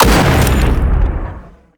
AntiMaterialRifle_3p_03.wav